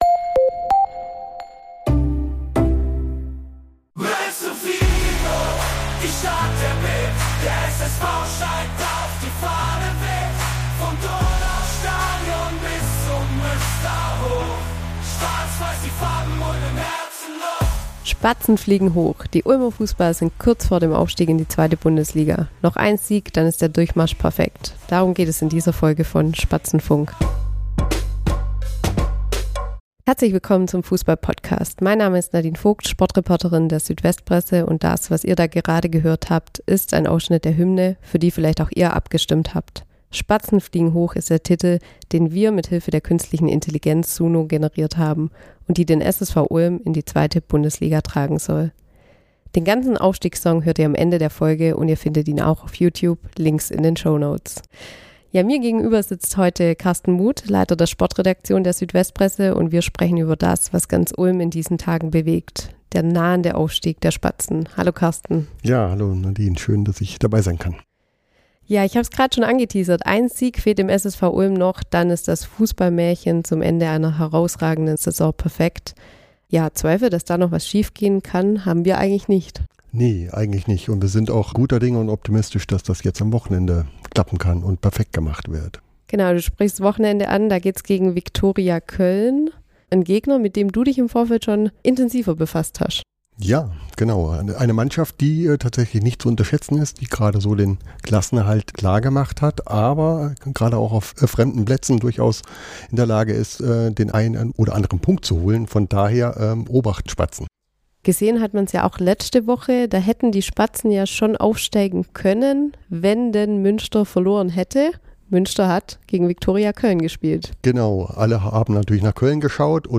Denn zum allerersten Mal ist die KI-Hymne „Spatzen fliegen hoch“ in voller Länge zu hören.